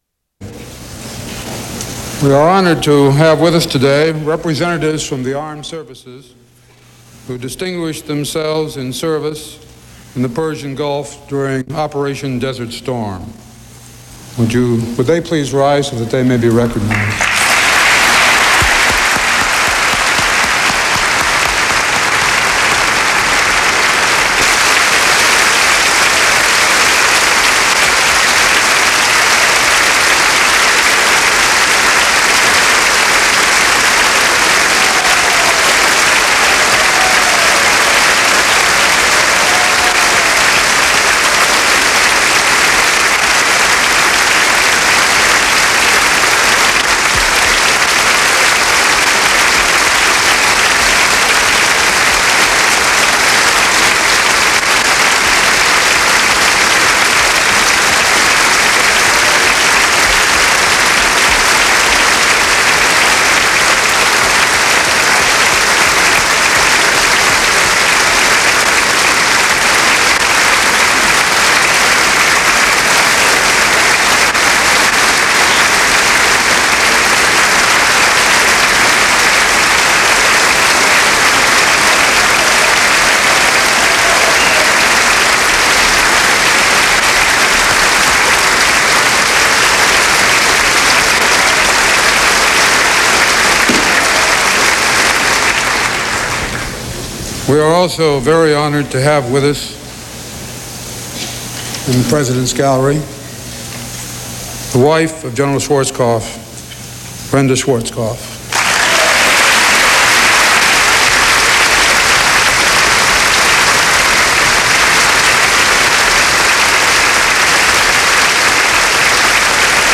Speaker of the House Thomas Foley introduces General Norman Schwarzkopf to a special joint session of Congress